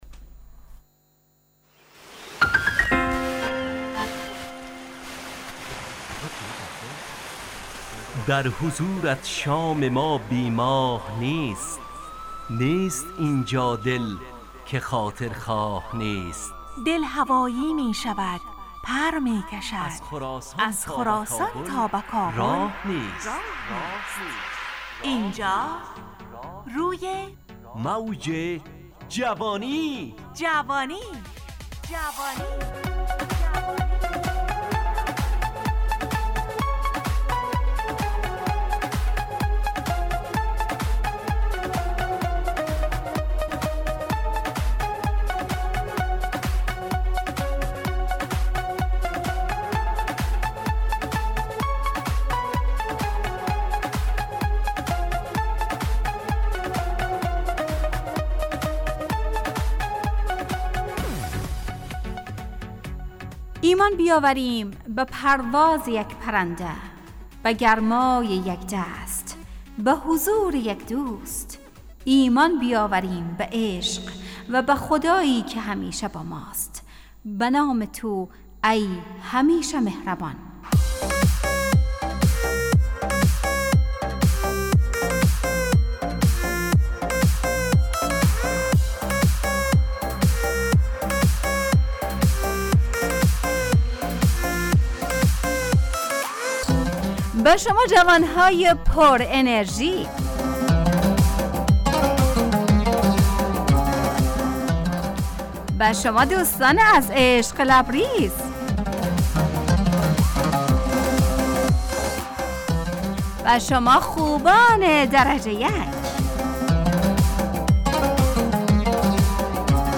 روی موج جوانی، برنامه شادو عصرانه رادیودری.
همراه با ترانه و موسیقی مدت برنامه 55 دقیقه . بحث محوری این هفته (امید) تهیه کننده